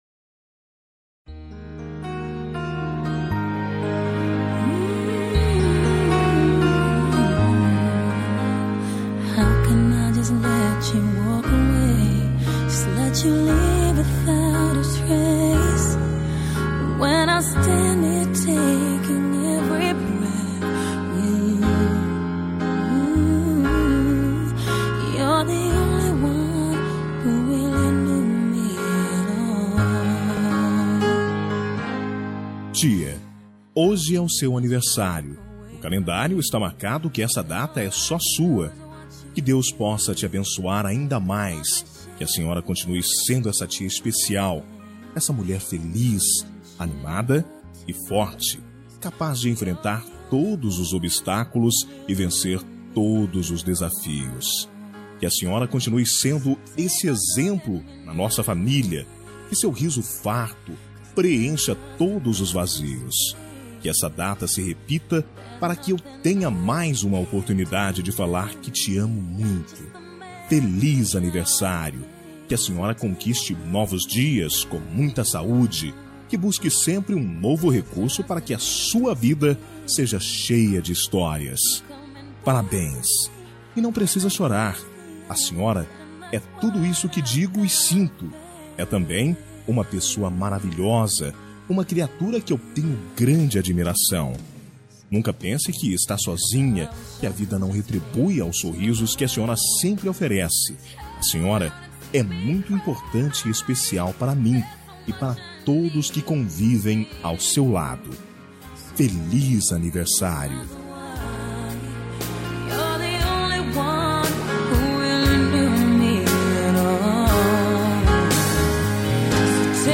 Aniversário de Tia – Voz Masculina – Cód: 940